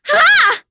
Nicky-Vox_Attack1.wav